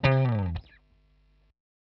120_Guitar_funky_slide_E_1.wav